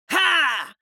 دانلود آهنگ دعوا 16 از افکت صوتی انسان و موجودات زنده
جلوه های صوتی
دانلود صدای دعوای 16 از ساعد نیوز با لینک مستقیم و کیفیت بالا